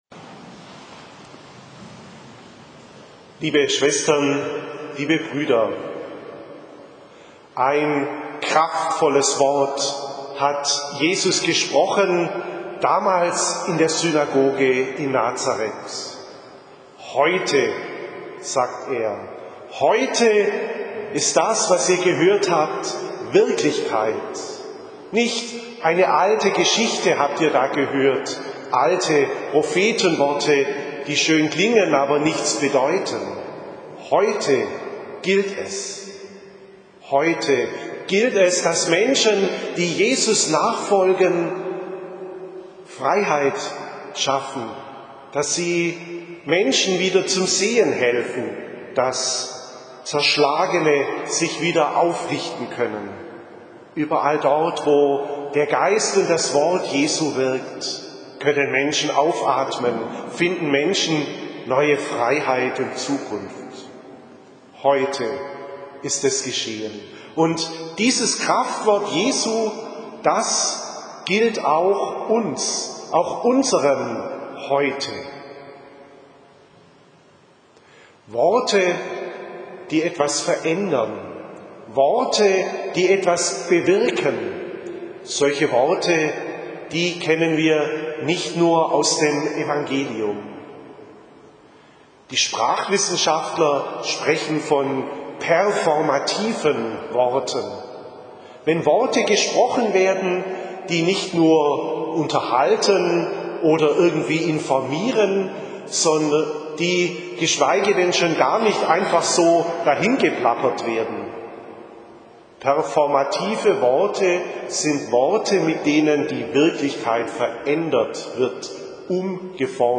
Predigt – Podcasts – Katholische Kirchen Esslingen
Hier finden Sie eine Auswahl von Predigtaufzeichnungen zum Anhören.